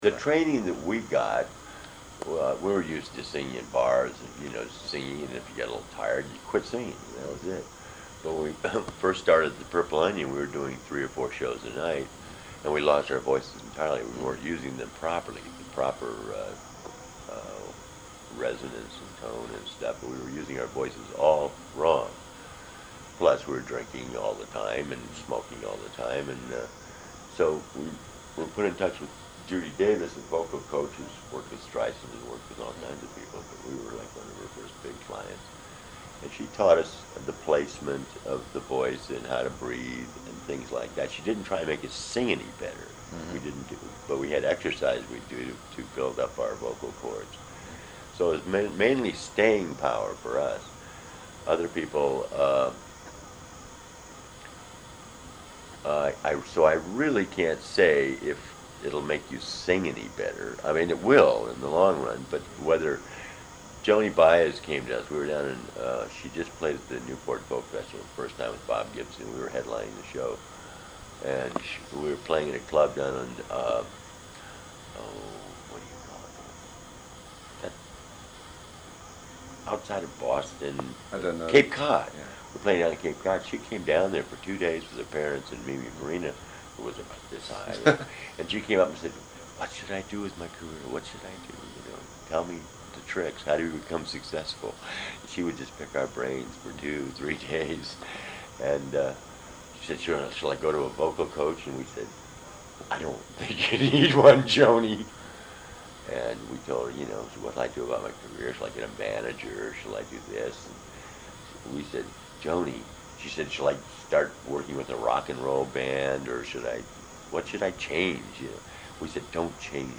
Here are some interviews I did when I was writing for GI (along with a few sound-bites)…